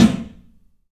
PABSNARE101R.wav